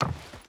Footsteps / Wood
Wood Run 3.wav